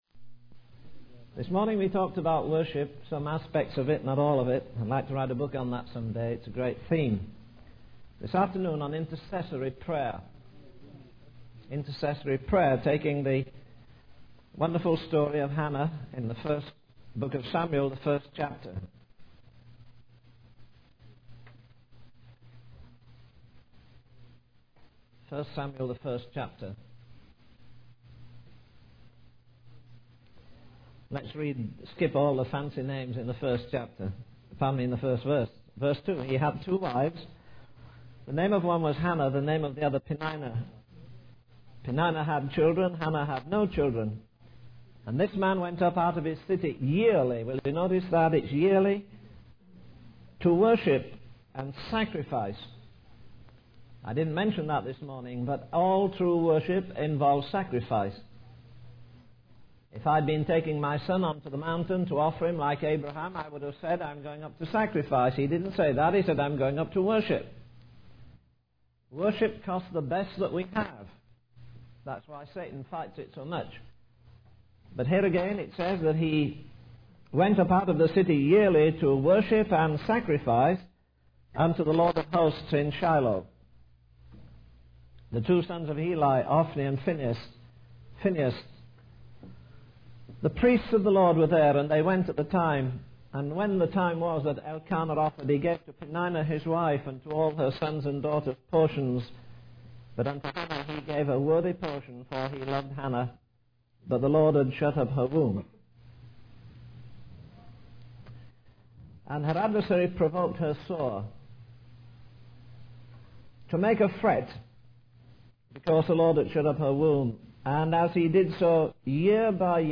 In this sermon, the preacher discusses the different perspectives of Jesus presented in the Gospels of Matthew, Mark, Luke, and John.